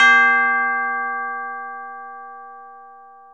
SouthSide Tubular Bell (3).wav